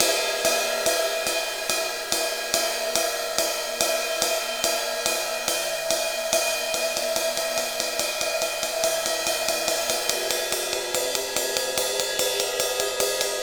Ride Cymbal Pattern 44.wav